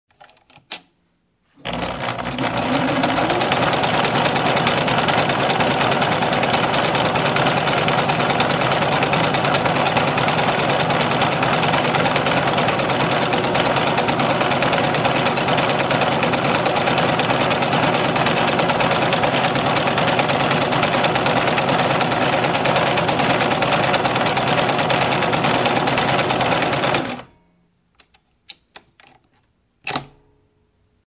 Звуки бытовой техники
Швейная машинка nШвейный аппарат nМашинка для шитья nУстройство для шитья nДомашняя швейная машина